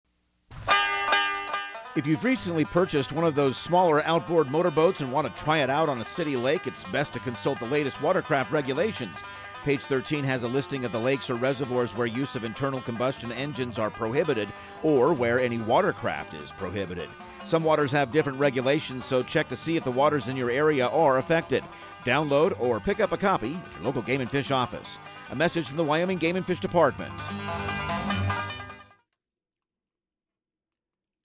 Outdoor Tip/PSA